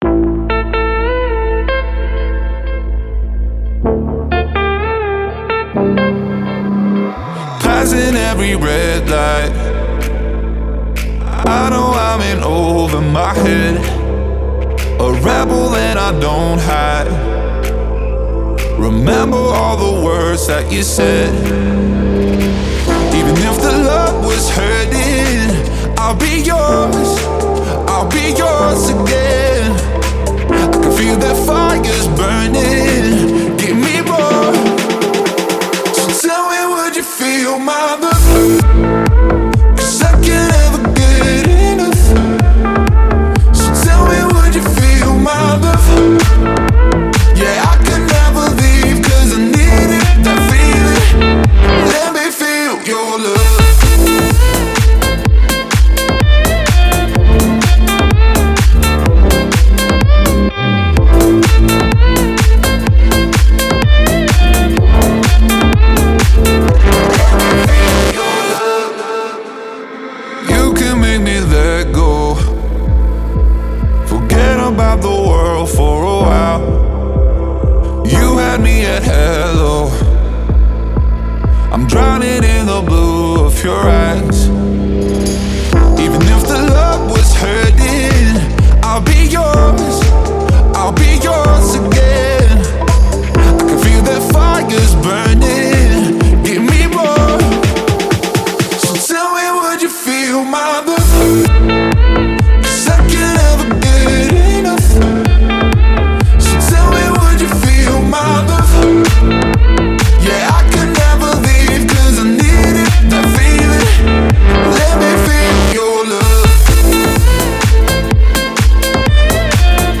это яркая и мелодичная трек в жанре EDM